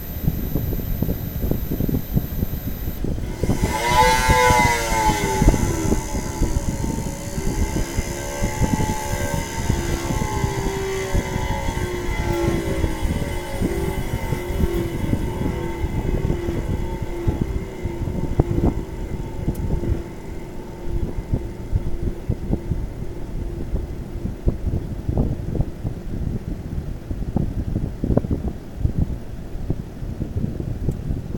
Bruit unité externe Daikin PAC air eau
J'ai pu enregistrer l'intégralité du bruit.
Bonjour, j'entends une forte accélération, comme un moteur qui serait brutalement débrayé, avant que le l'automate ne coupe tout par sécurité ?...
bruit-complet.mp3